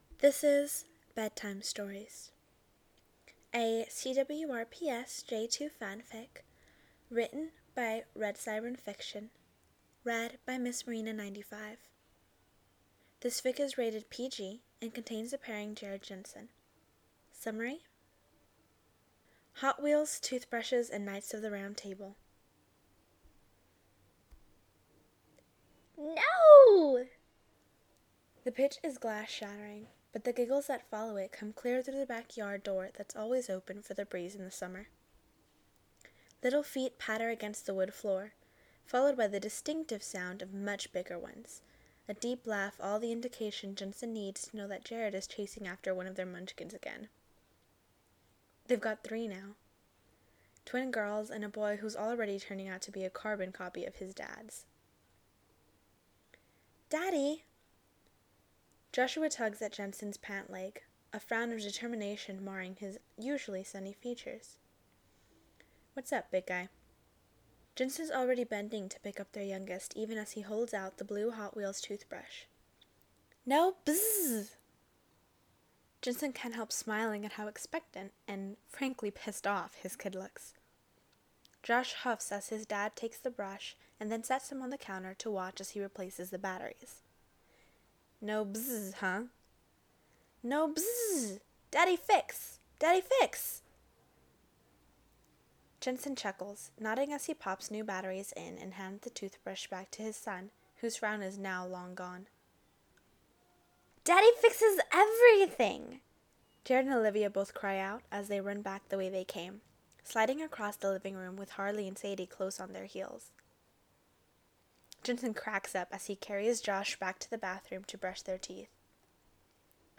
(J2 Podfic) Bedtime Stories
podfic, fandom:cwrps, challenge:podfic_bingo, pairing:j2, rating:pg